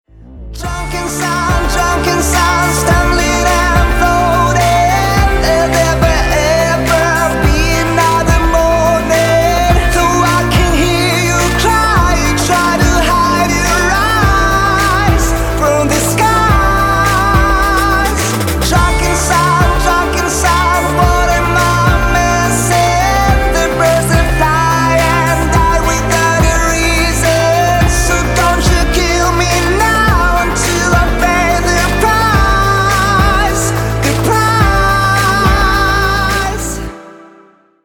• Качество: 192, Stereo
поп
громкие